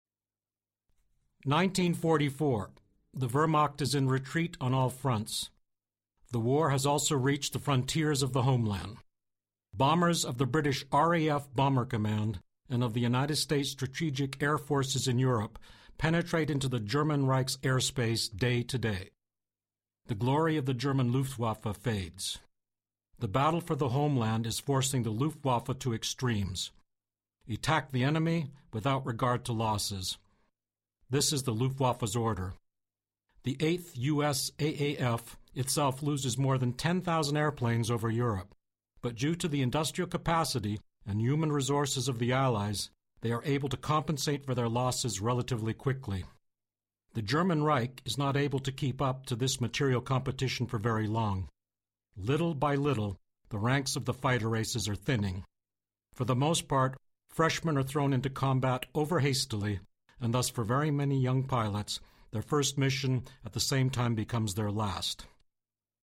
Excellent on educational, industrial, promotional voice-over (English-USA). Voice range - 35-60 years
Sprechprobe: eLearning (Muttersprache):